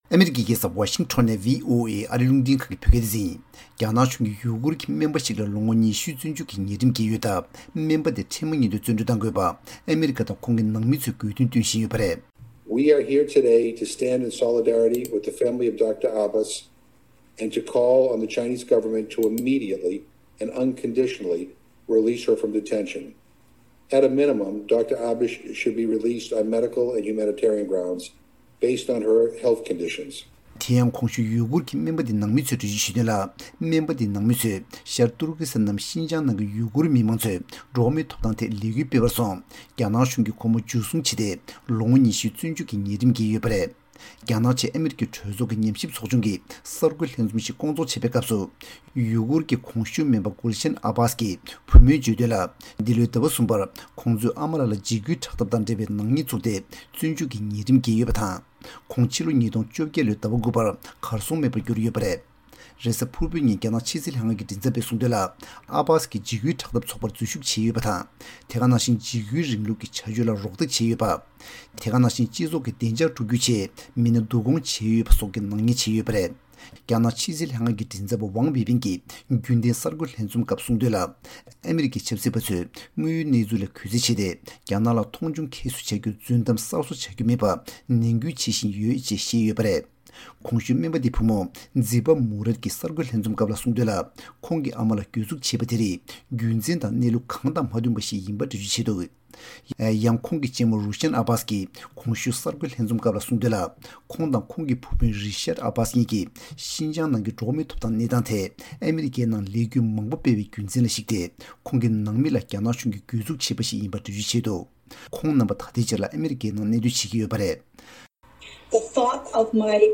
གནས་ཚུལ་སྙན་སྒྲོན་ཞུ་ཡི་རེད།།